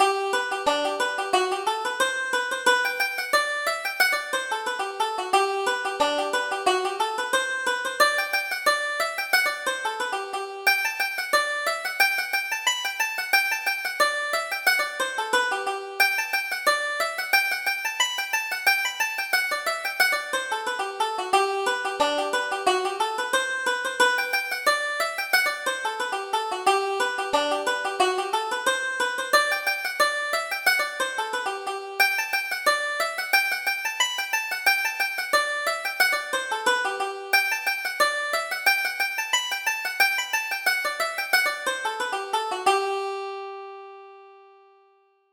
Reel: The Peeler's Jacket